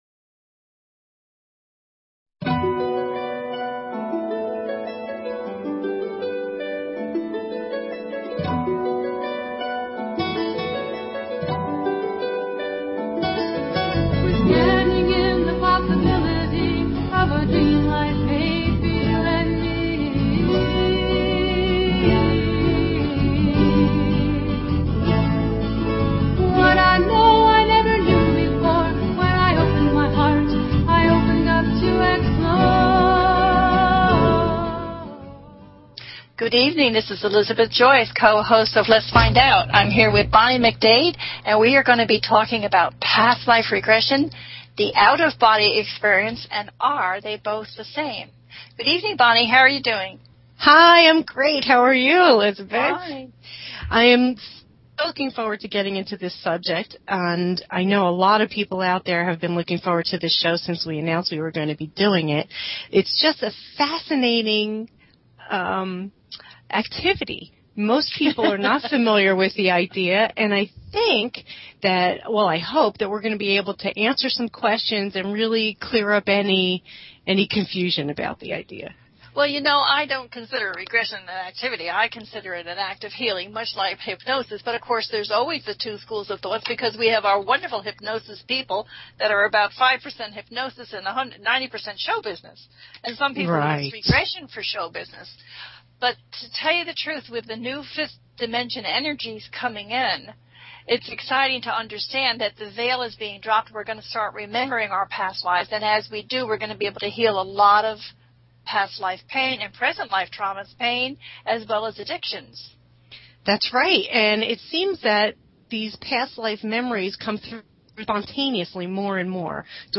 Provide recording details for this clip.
The listener can call in to ask a question on the air.